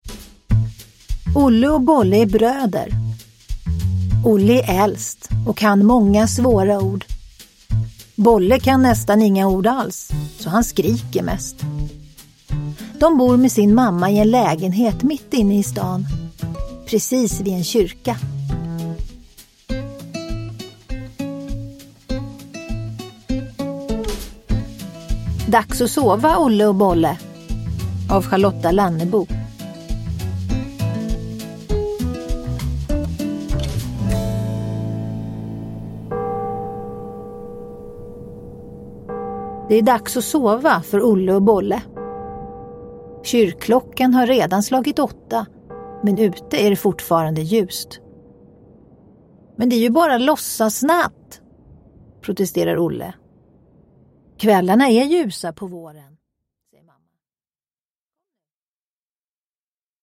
Dags att sova – Ljudbok – Laddas ner
Uppläsare: Tova Magnusson